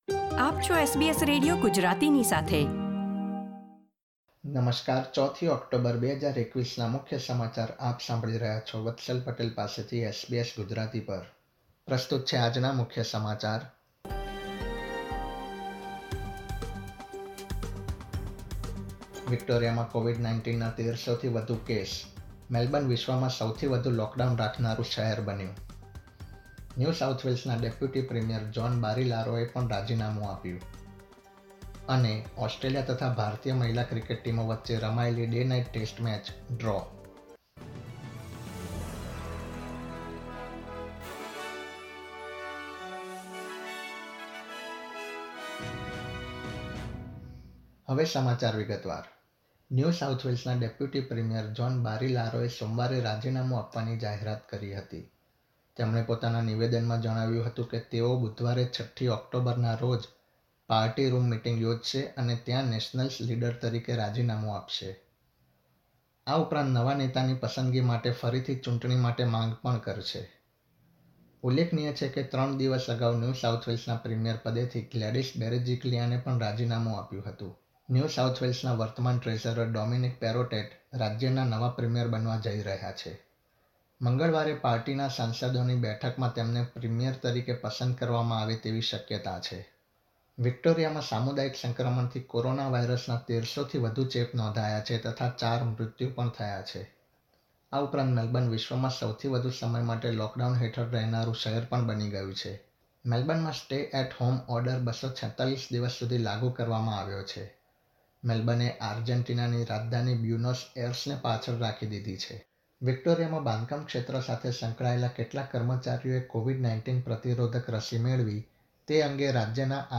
gujarati_0410_newsbulletin.mp3